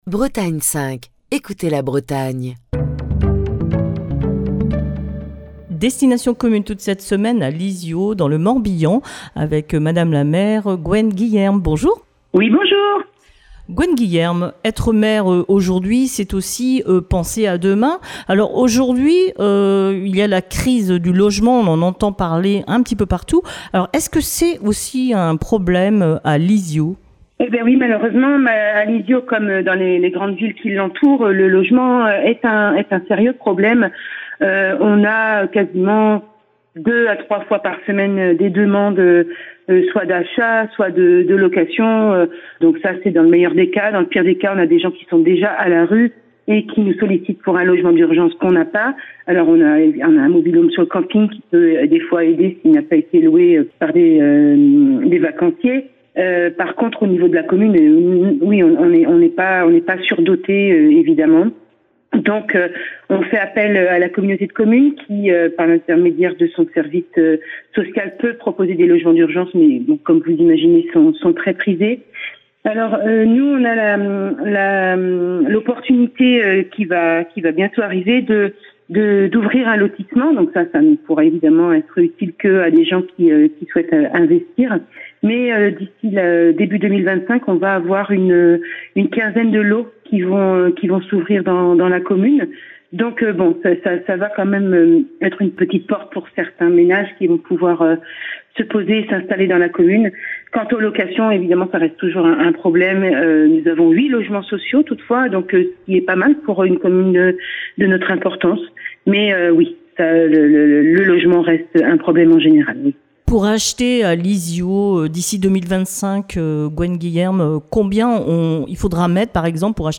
Cette semaine, Destination commune s'arrête à Lizio, dans le Morbihan. Gwen Guillerme, la maire de Lizio, vous propose de découvrir sa commune à travers les initiatives locales, les relations avec les habitants, mais aussi sa charge de maire et les projets pour l'avenir